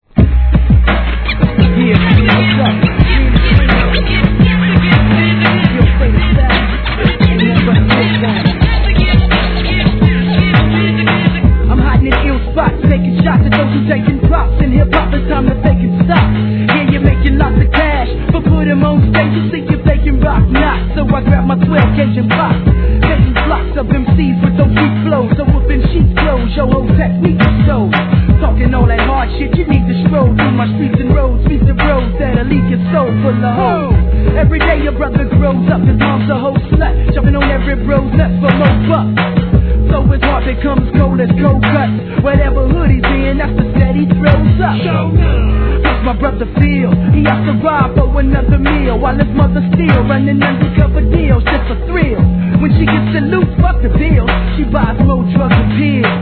HIP HOP/R&B
シカゴDOPEアンダーグランド!!